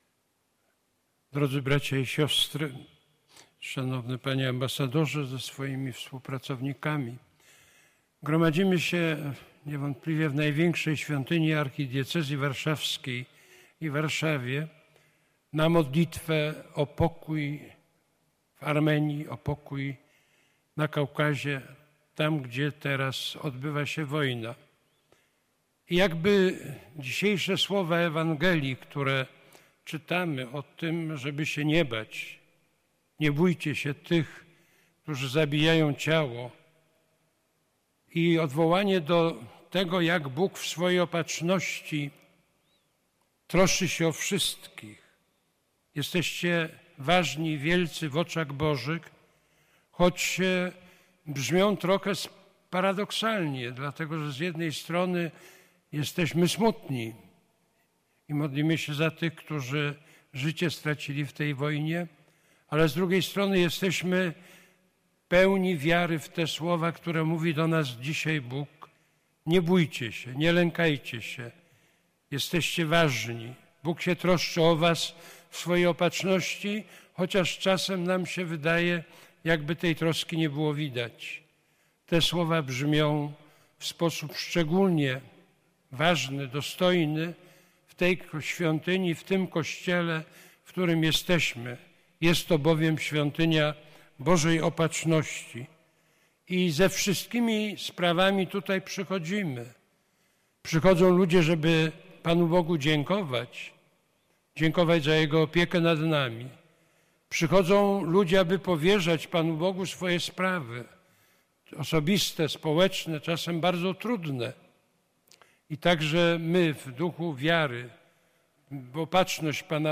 Homilię podczas Mszy Świętej wygłosił kardynał Kazimierz Nycz.
kardynal-Kazimierz-Nycz-16-pazdziernika-Msza-sw.-dla-Ormian.mp3